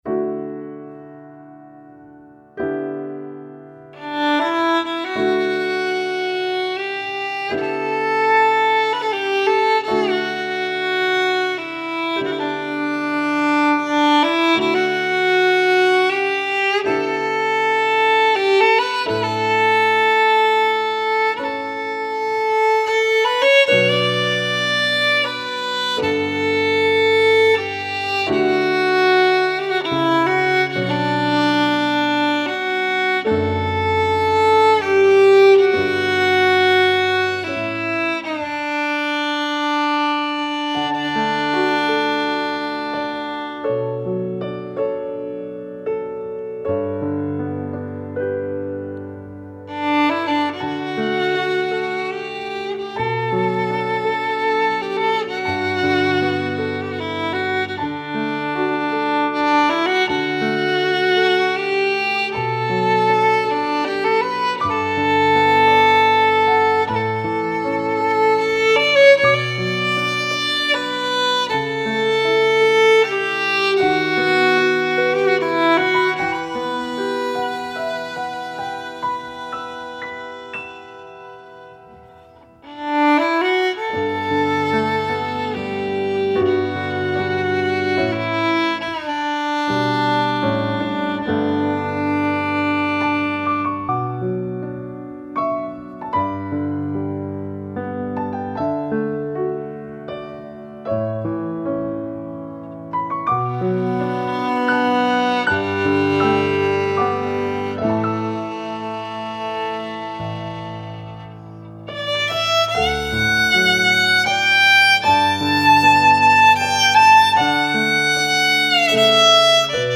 Instrumentation: violin and piano